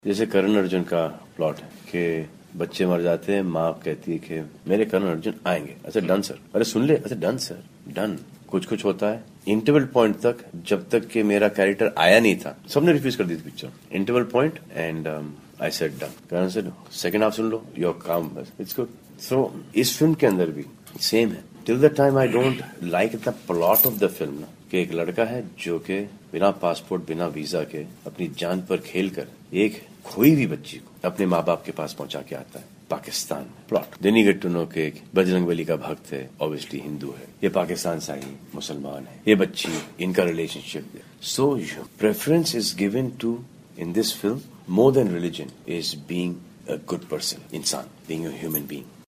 सुनिए अभिनेता सलमान ख़ान से क्यों बने वो फ़िल्म 'बजरंगी भाईजान' के निर्माता.